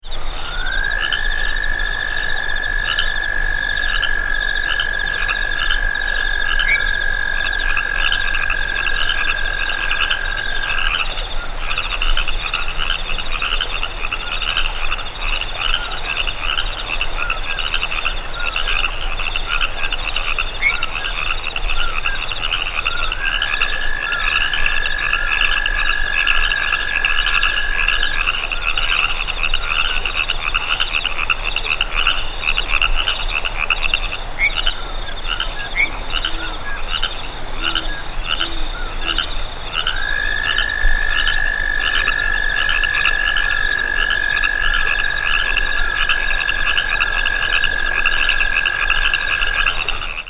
The advertisement call of the Arroyo Toad is a fast musical trill, about 10 seconds, rising in pitch, and ending abruptly.
Sound This is a 50 second recording of a series of advertisement calls of a distant male Arroyo Toad calling at night in a riparian canyon in San Diego County (shown to the right.) Continuous calls of crickets and of Pseudacris regilla - Pacific Treefrogs, and occasional calls of Pseudacris cadaverina - California Treefrogs, are heard in the foreground, while in the background are running water, the calls of a Common Poorwill, and the faint call of a Great Horned Owl near the end of the recording.